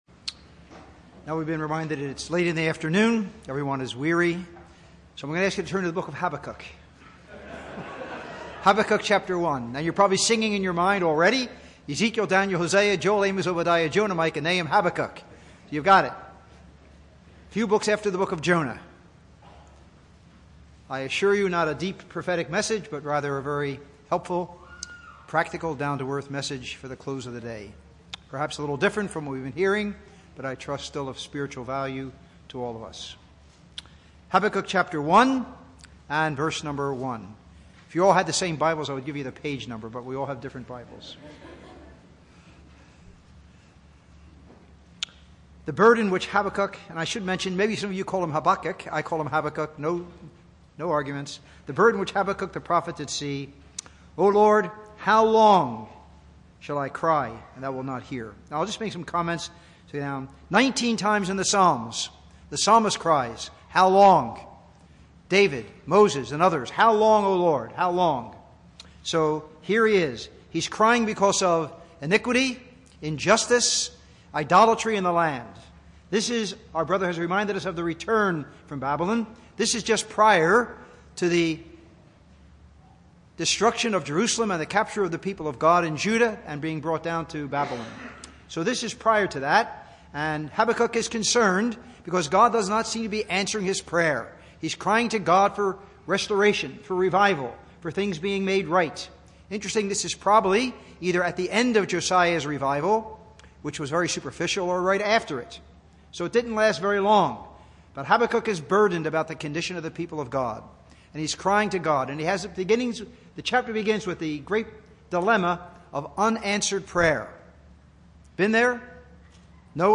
Labor Day Conference 2025